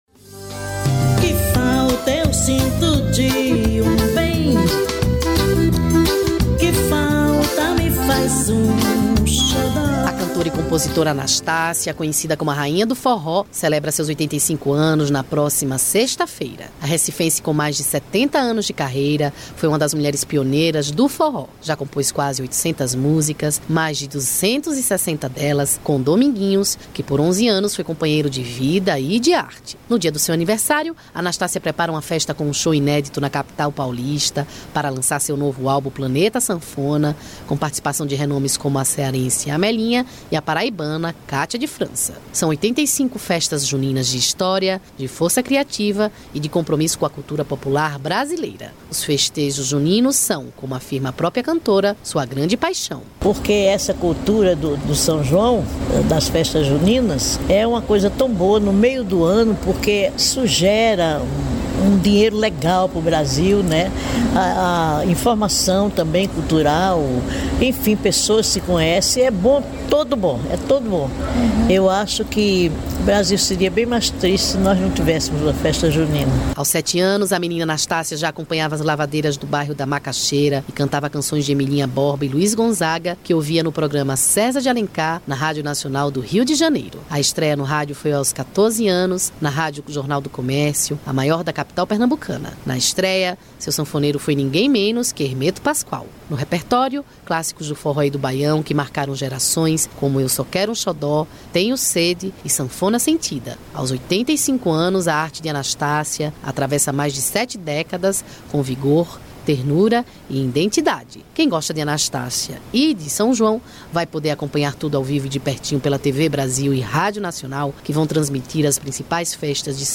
São Paulo (SP), 27/05/2025 - Cantora Anastácia, em entrevista para a Rádio Nacional SP.